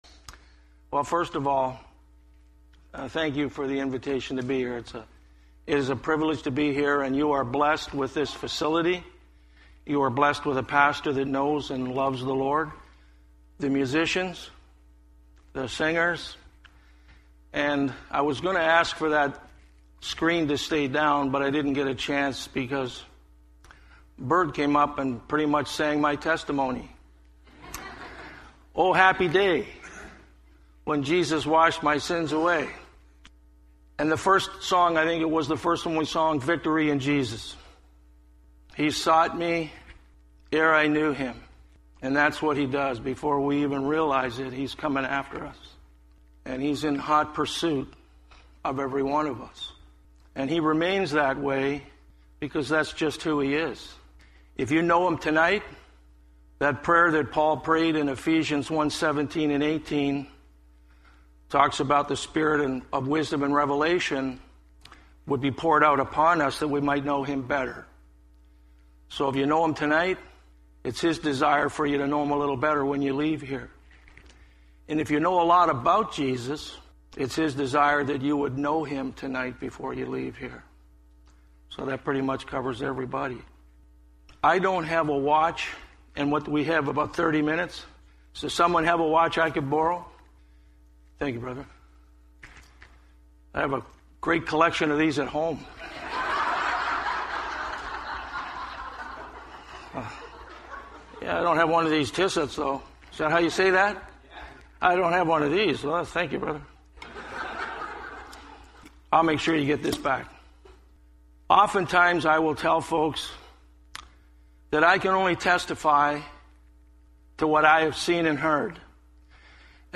(Recorded at Byron United Church in London, Ontario on Nov. 3rd, 2013)